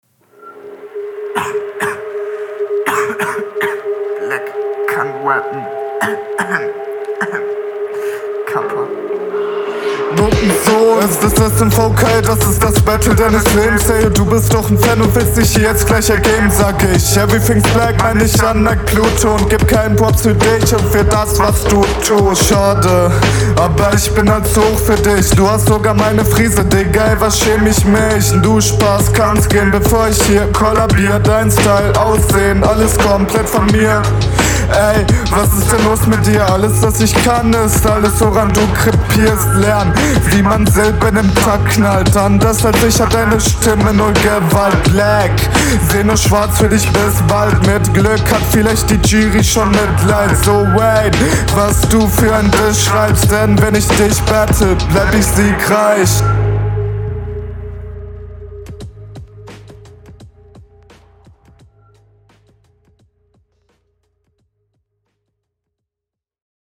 - Mix klingt auf eine ganz andere Art ungeil, als bei deinem Gegner, aber besser. …